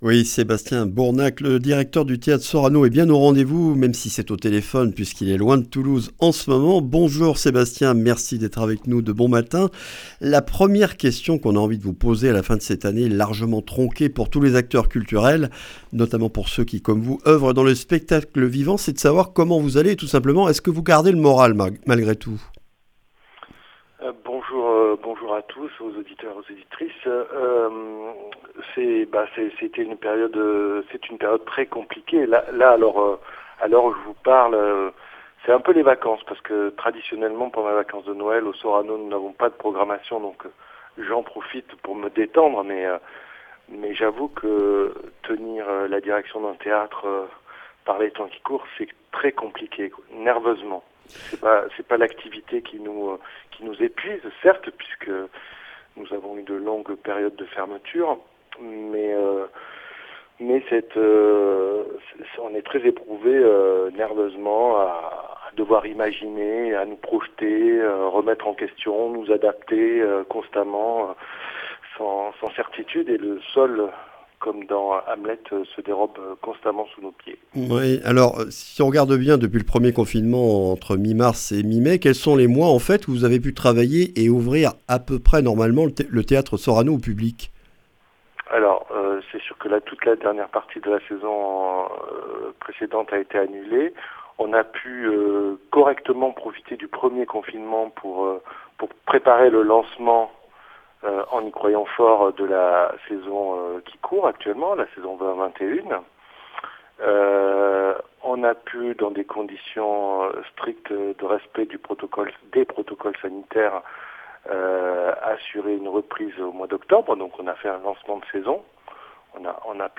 Accueil \ Emissions \ Information \ Régionale \ Le grand entretien \ Quelles perspectives pour le spectacle vivant en 2021 ?